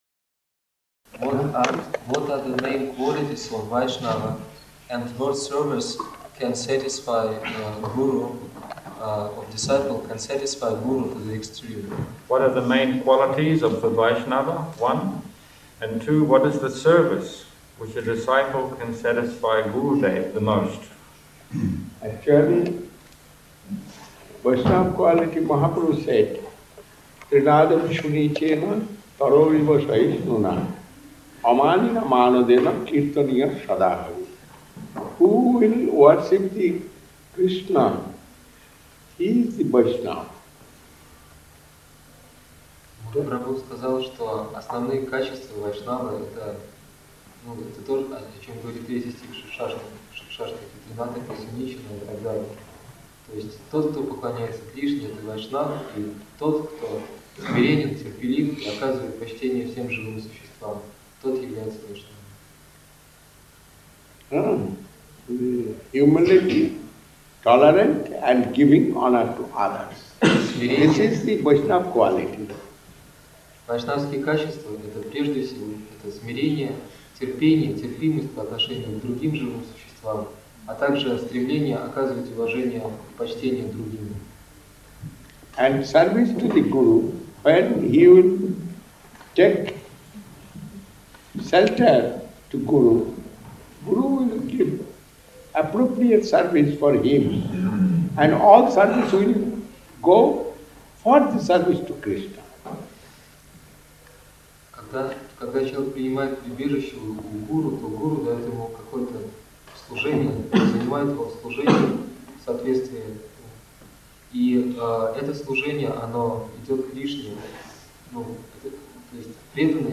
Тот, кто действительно обладает Кришна-премой, чувствует, что у него ее нет. Вайшнавский бхаджан Джайа Радха-Мадхава.
Место: Культурный центр «Шри Чайтанья Сарасвати» Москва